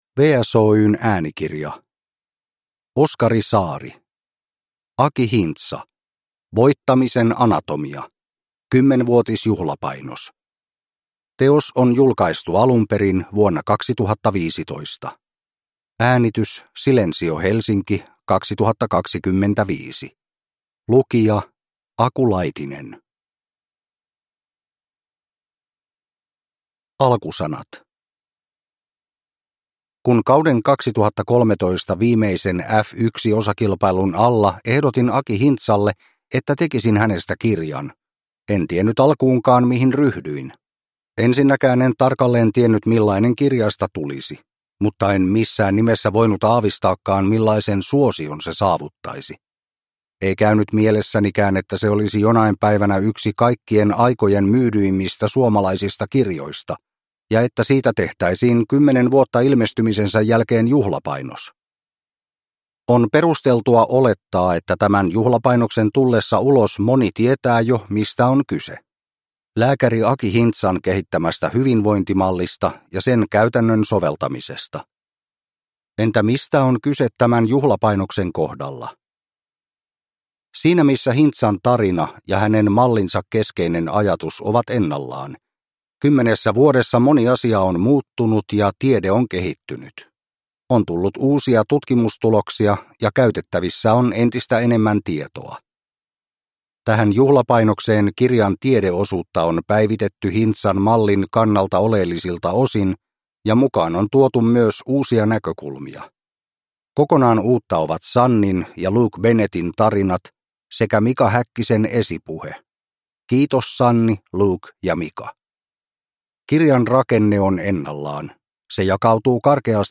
Aki Hintsa - Voittamisen Anatomia. 10-vuotisjuhlapainos (ljudbok) av Oskari Saari